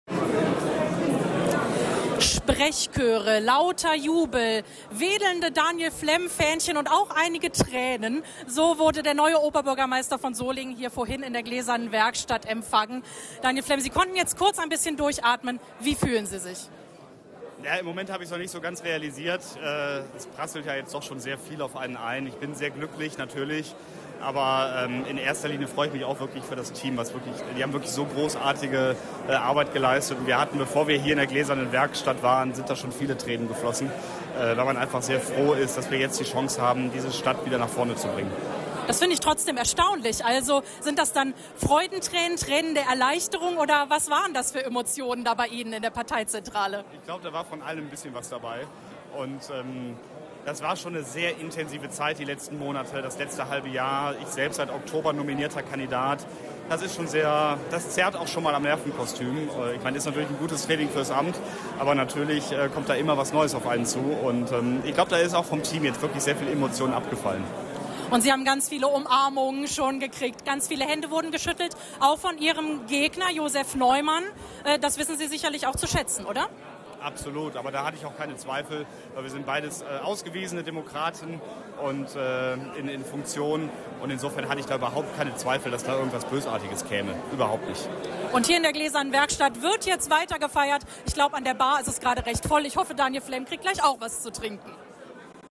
Die Stimmen der Gewinner und Verlierer